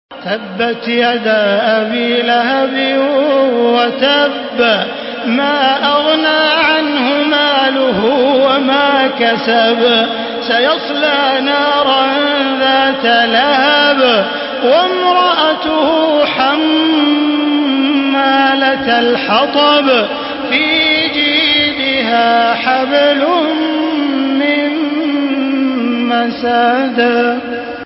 Surah আল-মাসাদ MP3 by Makkah Taraweeh 1435 in Hafs An Asim narration.
Murattal Hafs An Asim